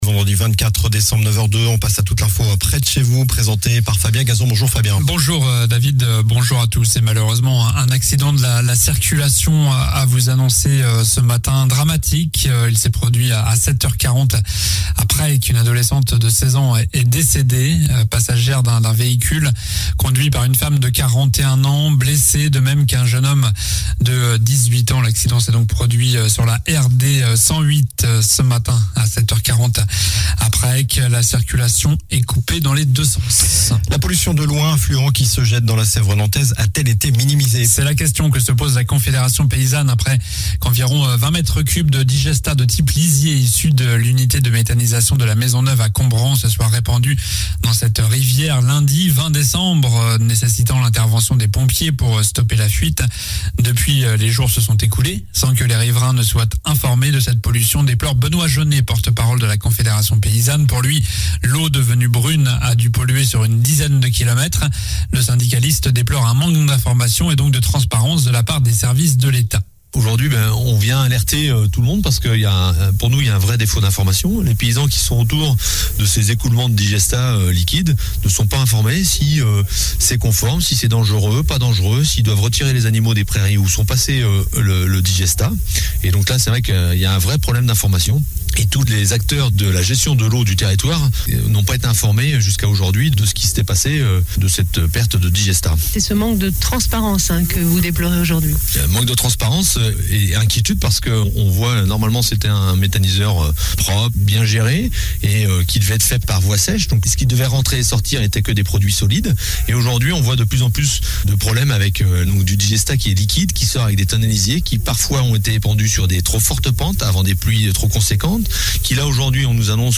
Journal du vendredi 24 décembre (matin)